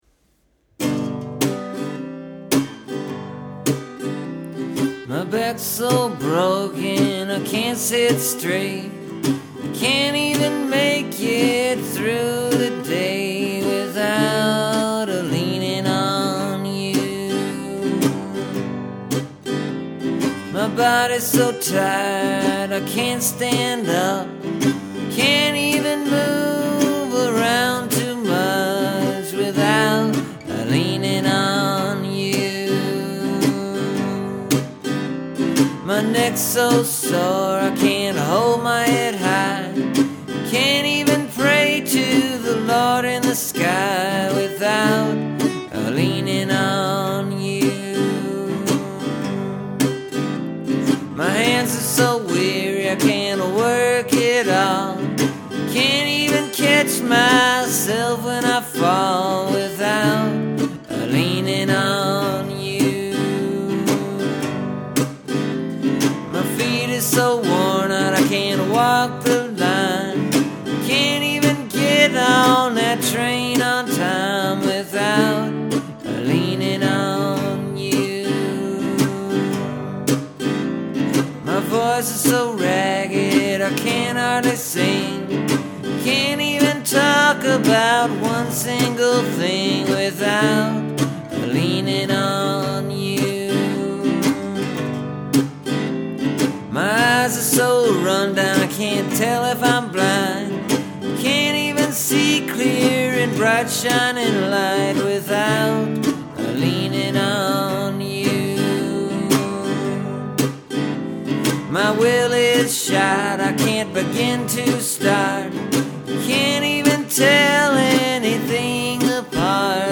Feels like it needs a chorus. Like it builds to something and just keeps on building up and up to nowhere.